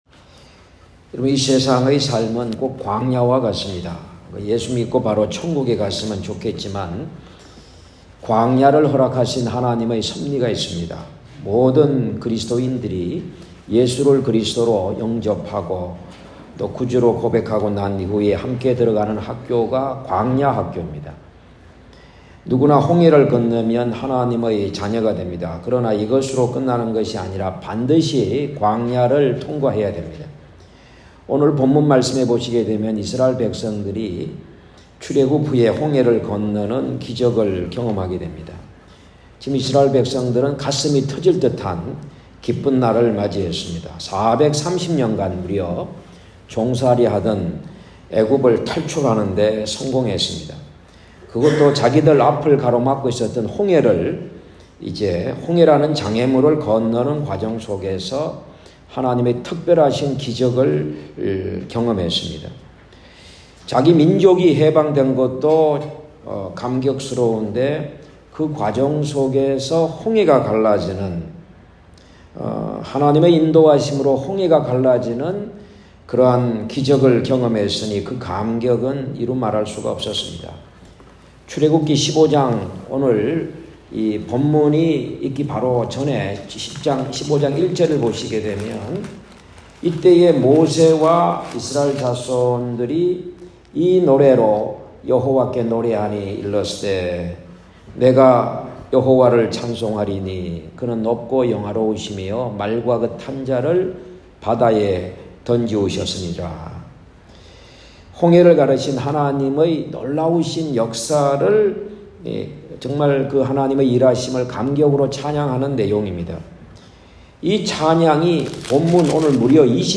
2024년 3월 17일 주일설교(1부) “하나님의 치료가 일어나는 교회(출15:22-27)”